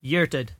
[yeER-tid]